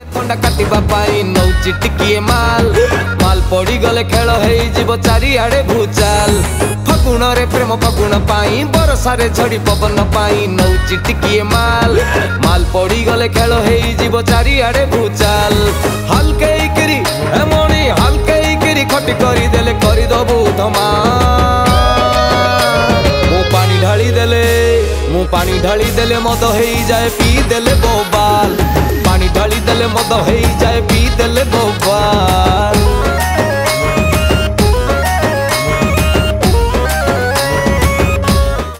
dance song